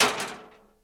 fullmetalbang.ogg